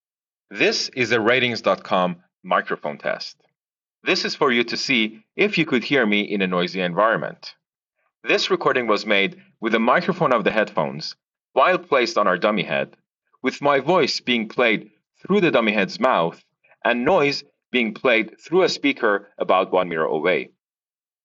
our recording to hear what speech sounds like through the Sony.